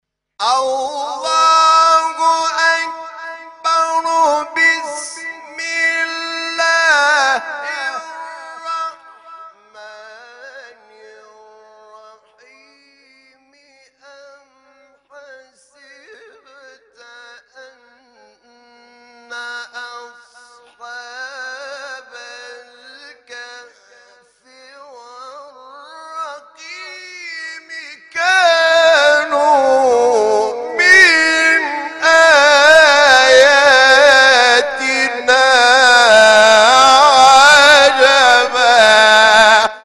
سوره : کهف آیه: 9 استاد : حامد شاکرنژاد مقام : رست قبلی بعدی